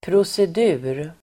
Uttal: [prosed'u:r]